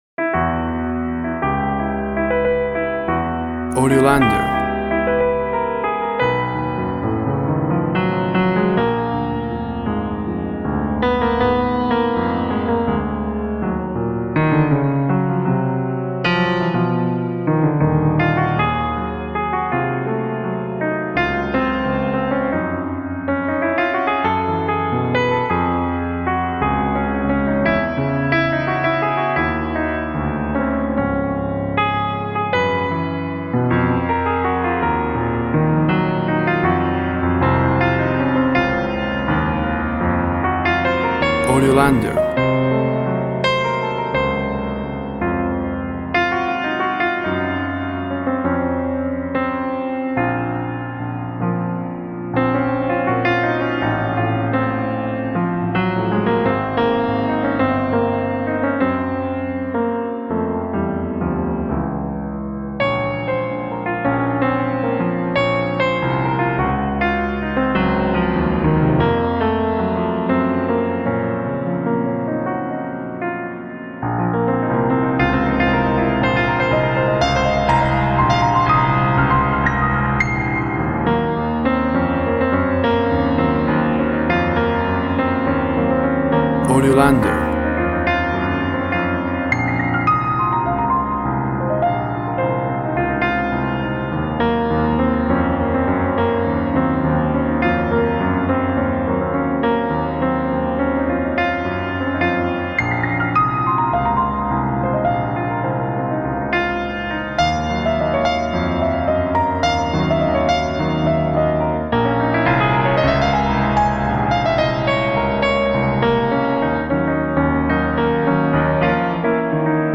Emotive and Melamcholic Tango Piano.
Tempo (BPM) 80